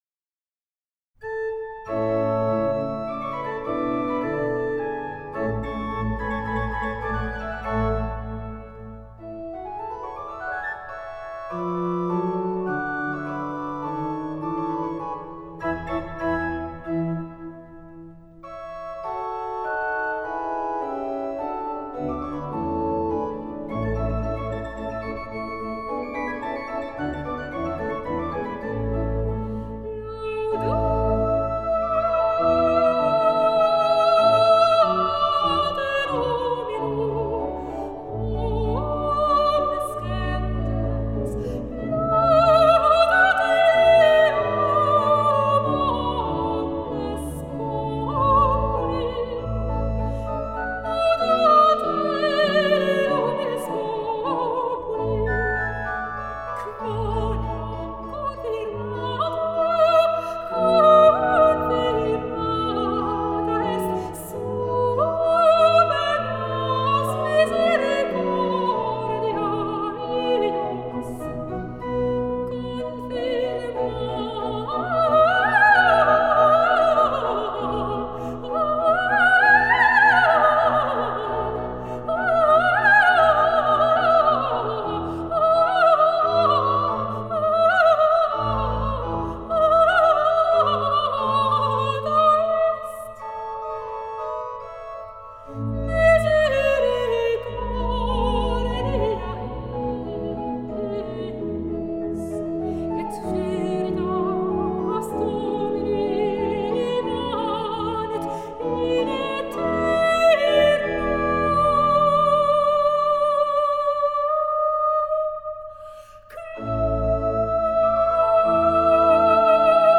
Sopran
Orgel